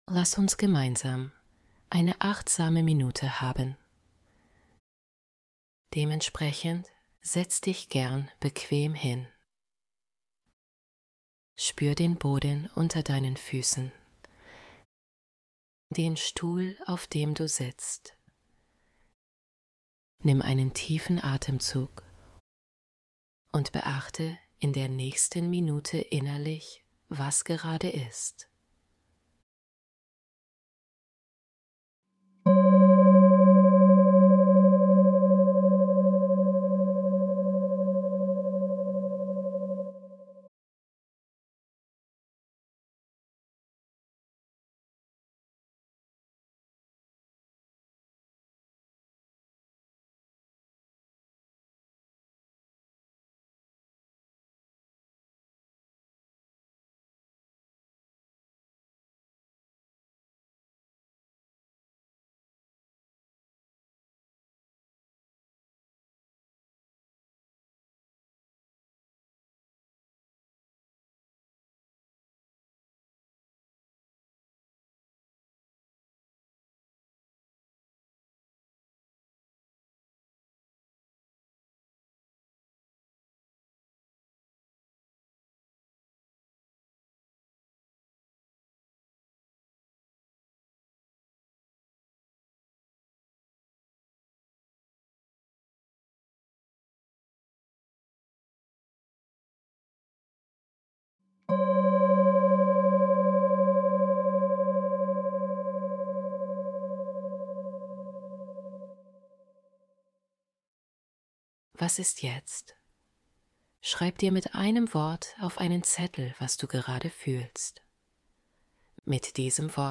Geführte Meditationen
~ 1 Minute Weibliche Stimme
achtsame-minute-female.mp3